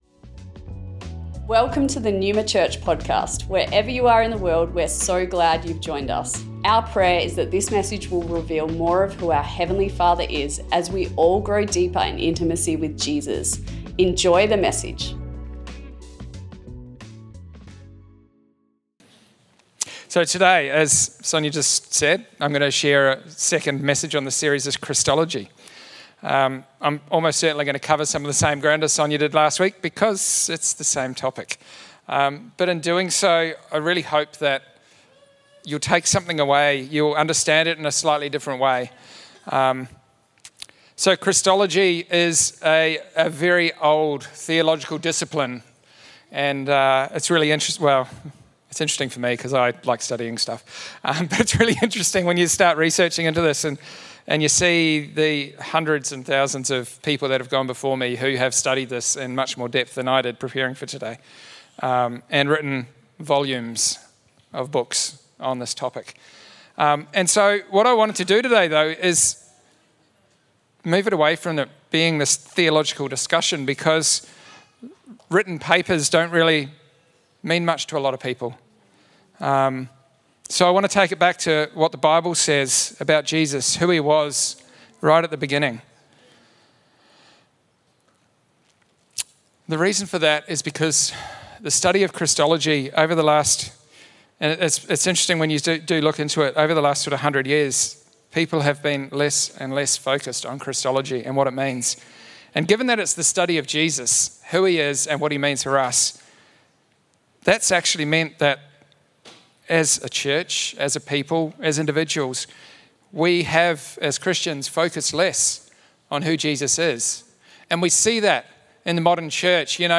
Recorded at Melbourne Neuma West